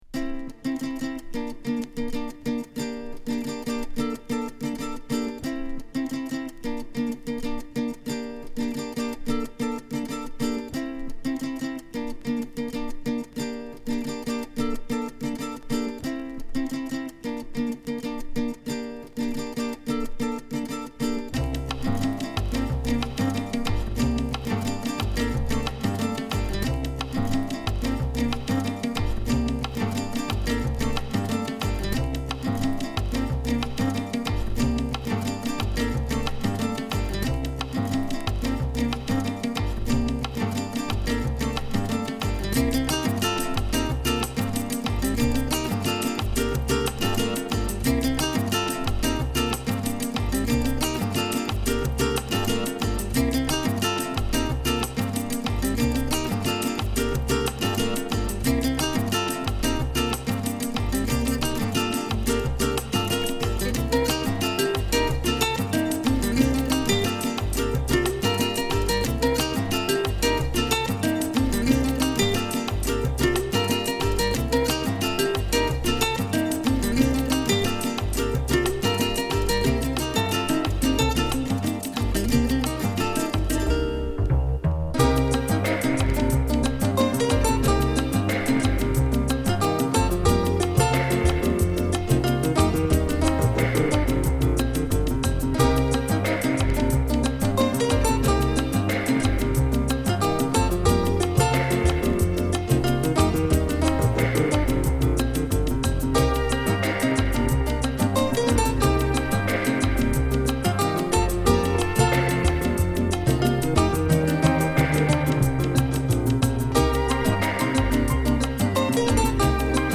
Mellow Groove